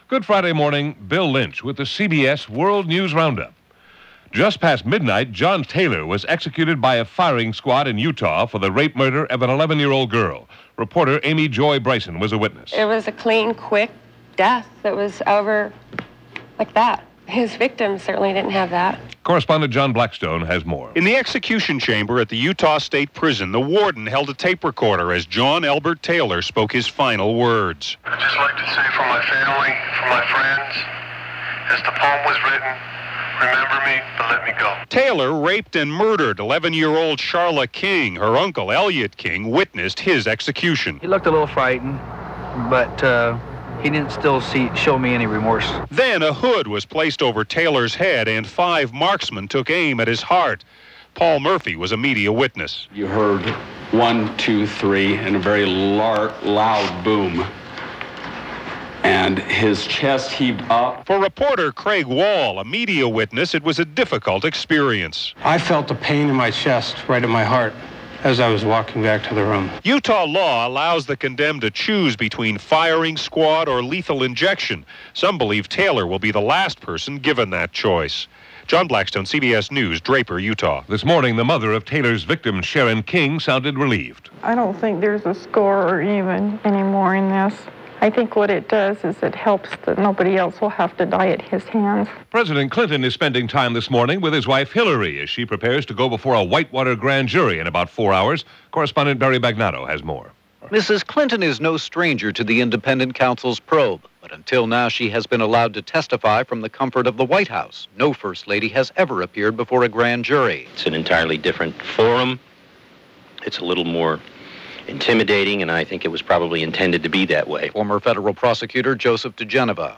All that, and so much more for January 26, 1996 as presented by The CBS World News Roundup.